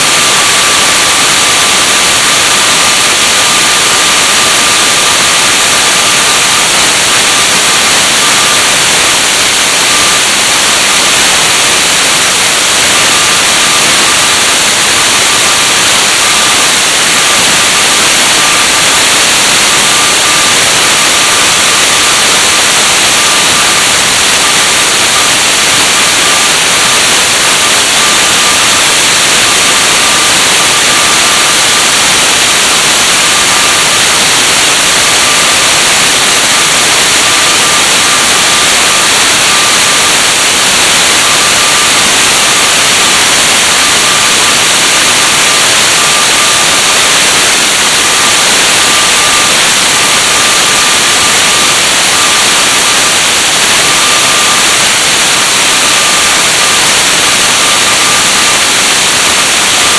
Both of the about QSOs were made using MCW and wideband FM IF receivers.
322GHz QSO Audio
This is the best sounding audio file I have. It's a raw recording of the wide band audio output of the R-7000 made with a Sony Mini-Disc recorder. It sounds much better if you play it back through an audio equilizer or a cheap pair of headphones to cut out the white noise. The audio tone is around 1200Hz.